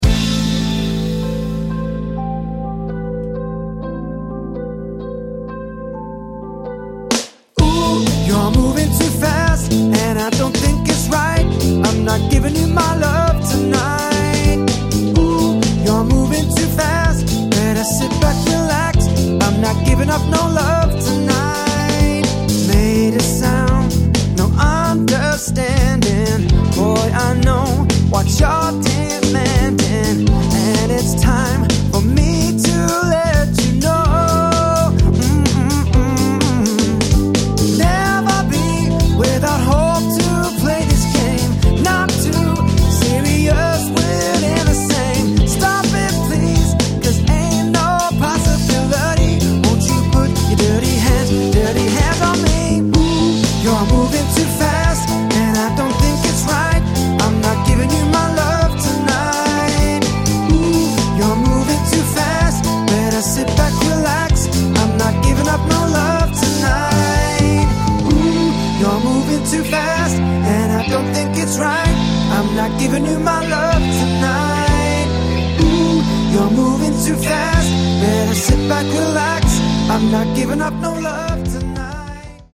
3-piece and 4-piece London Function Band